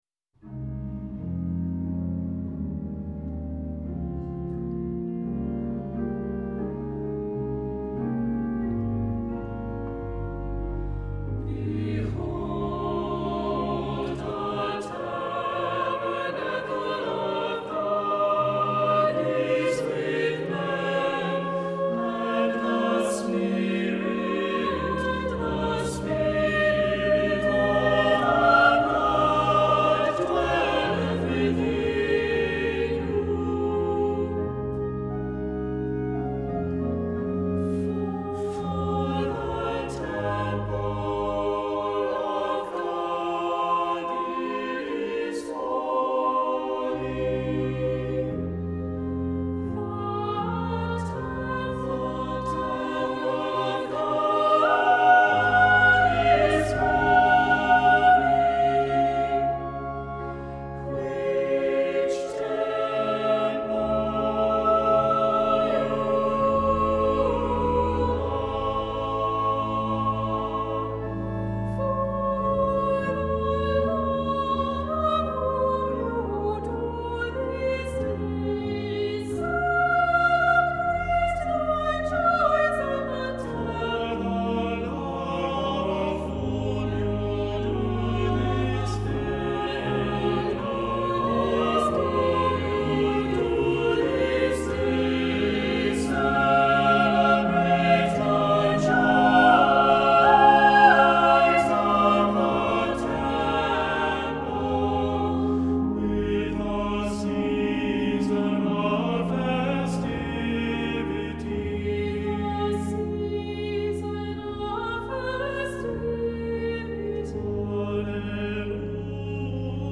Accompaniment:      With Organ
Music Category:      Christian